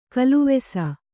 ♪ kaluvesa